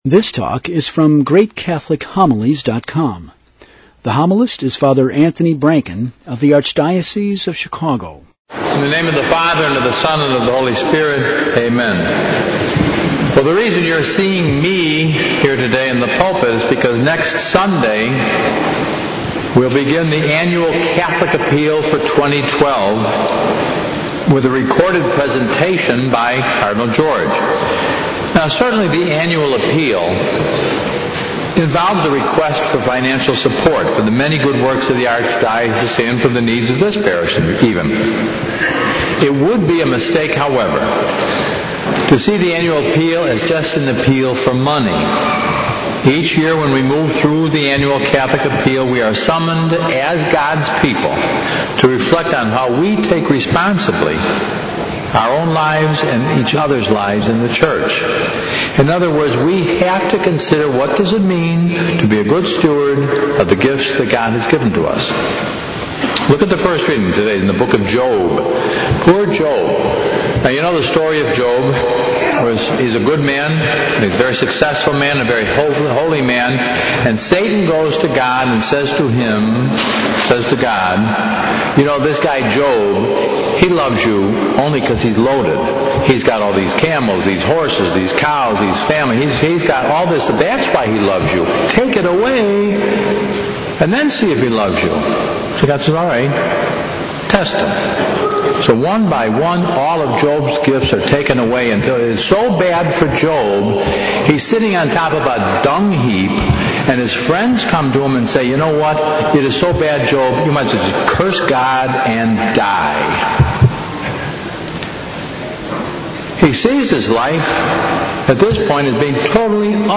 Sermon summary: The reading from the book of Job shows us how much suffering Job went through.